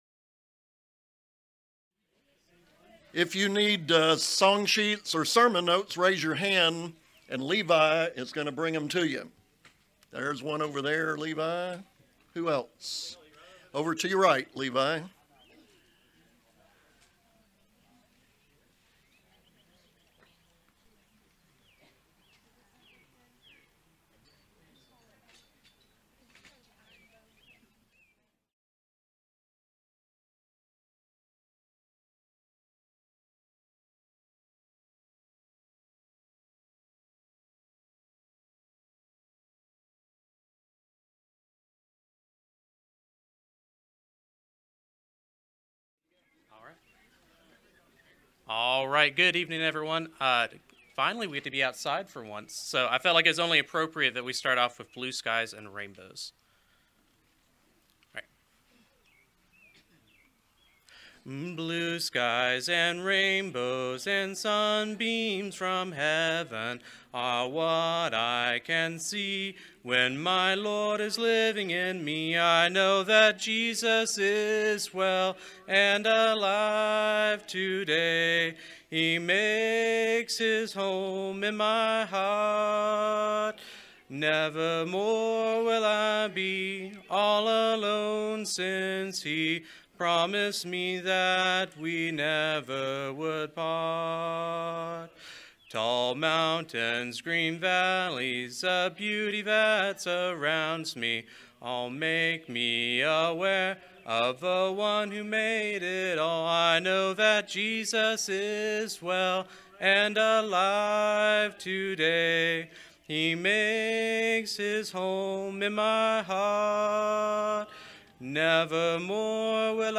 Matthew 5:48, English Standard Version Series: Sunday PM Service